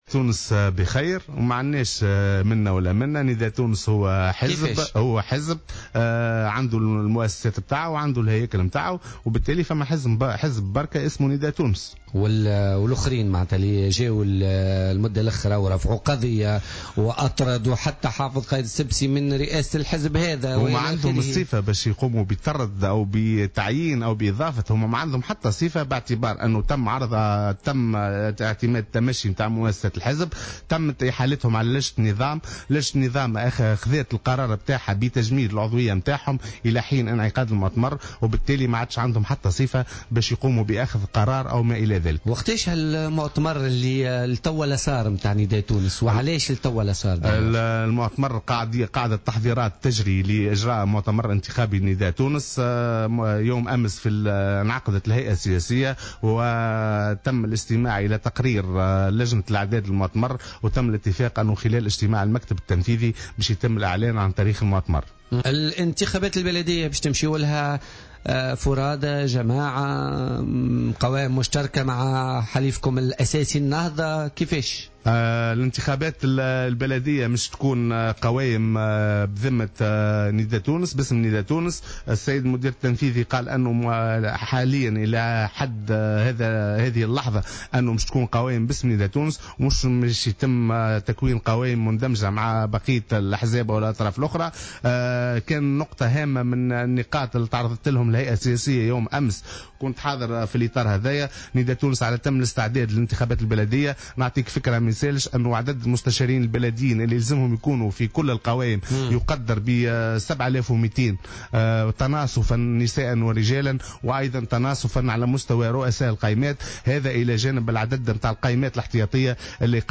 وأضاف بن حسن ضيف برنامج "بوليتيكا" اليوم أنه تمت إحالتهم على لجنة النظام واتخذت قرارات بتجميد عضويتهم إلى حين انعقاد المؤتمر القادم للحزب وبالتالي لم يعد لهم أي صفة قانونية لأخذ القرارات.